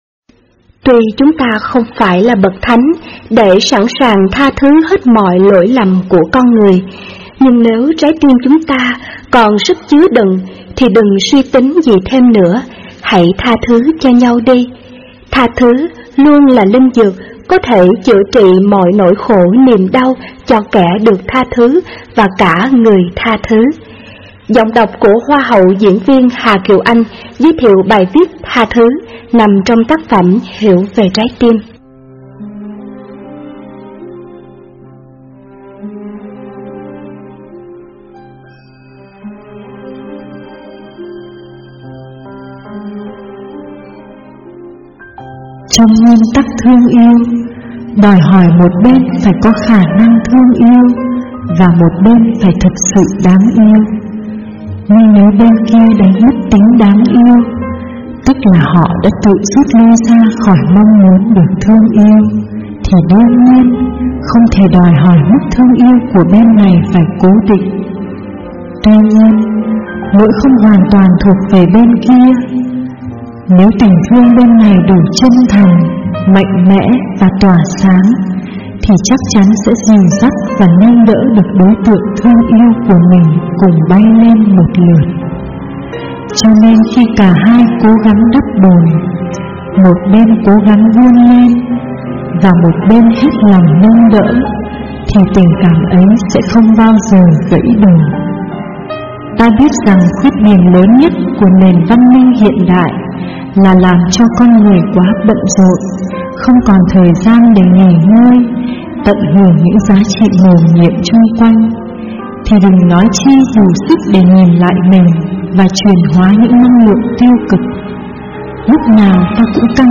Thích Minh Niệm với Giọng đọc Hà Kiều Anh Hiểu về trái tim Sách nói mp3